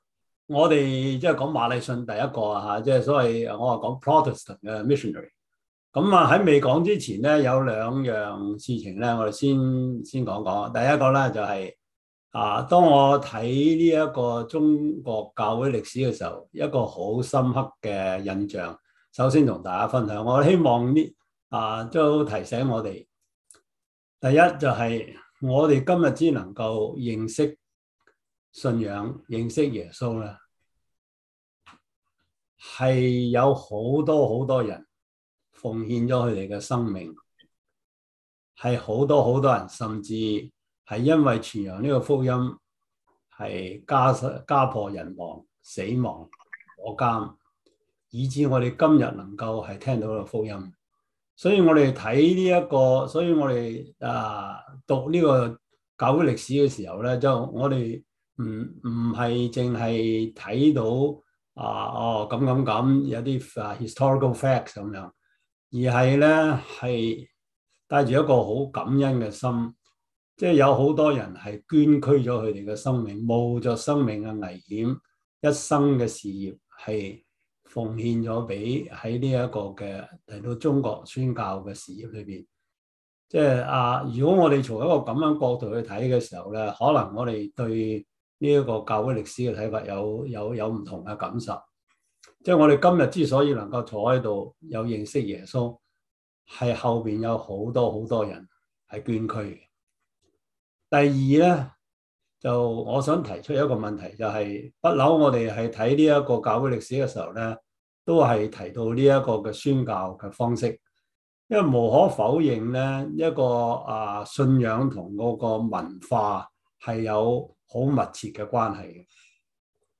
Service Type: 中文主日學